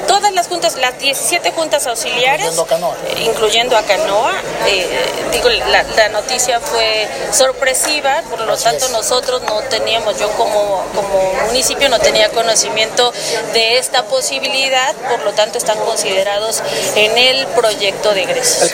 En entrevista, la presidente municipal abundó que todos los cambios que se deban efectuar en torno a la municipalización de alguna junta auxiliar deben contar con una planeación, a fin de que se retome cualquier cambio en el proyecto de egresos que se aplicará para el año 2020, para que se logre un desarrollo y que no resulte limitado.